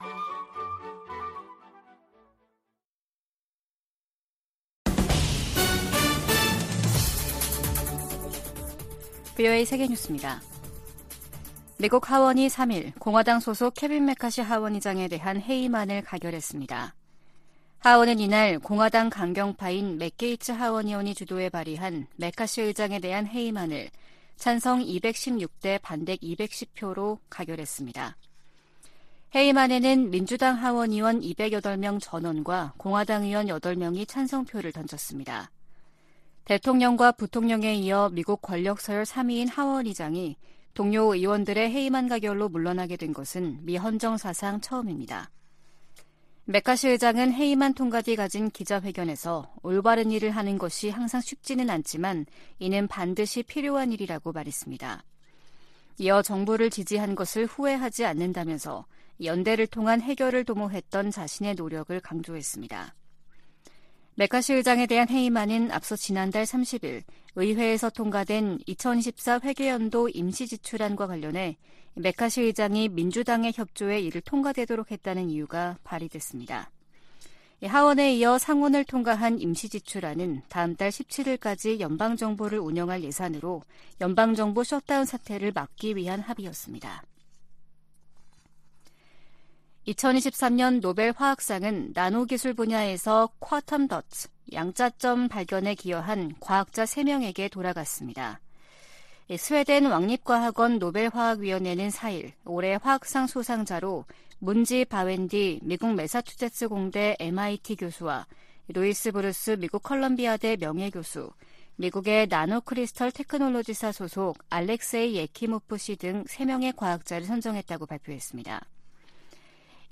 VOA 한국어 아침 뉴스 프로그램 '워싱턴 뉴스 광장' 2023년 10월 5일 방송입니다. 북한 국방성은 미국의 '2023 대량살상무기(WMD) 대응 전략'을 "또 하나의 엄중한 군사정치적 도발"이라고 규정했습니다.